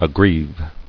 [ag·grieve]